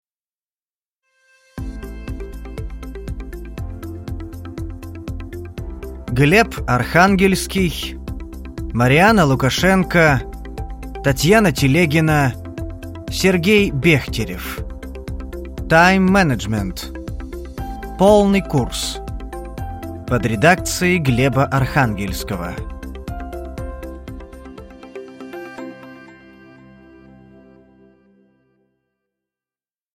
Аудиокнига Тайм-менеджмент: Полный курс | Библиотека аудиокниг